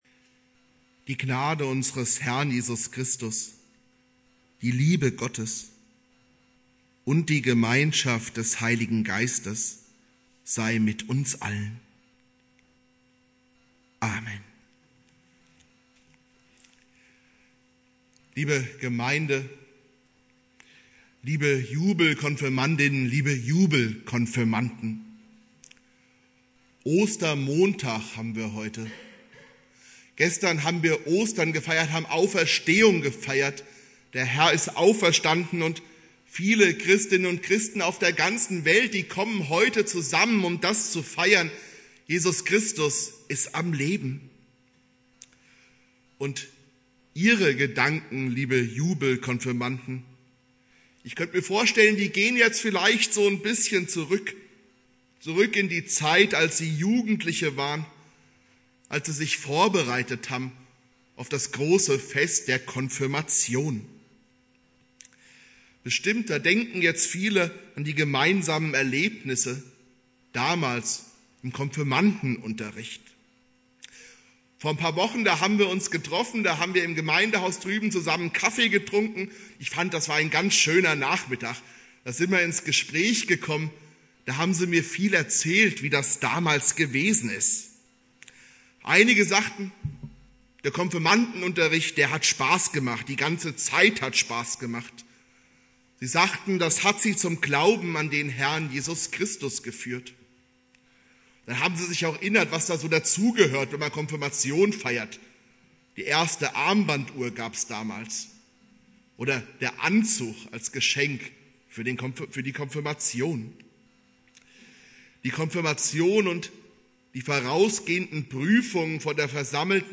Predigt
Ostermontag